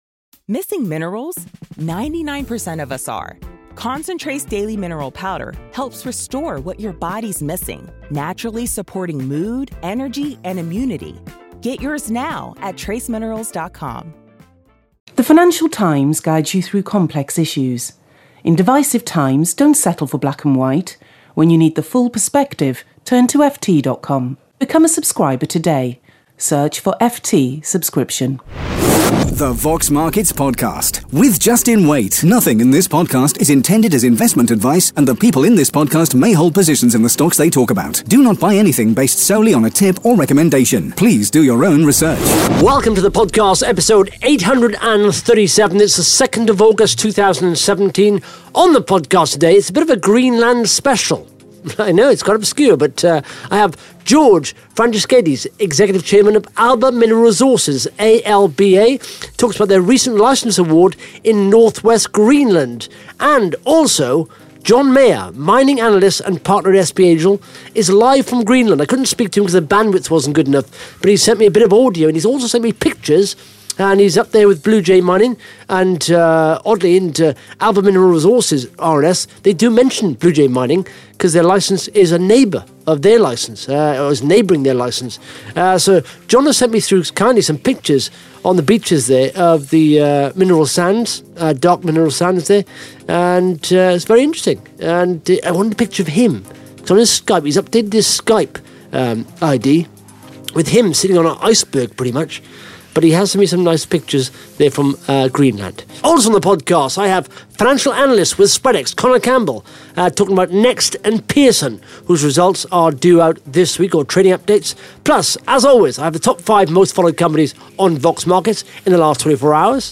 (Interview starts at 1 minute 51 seconds)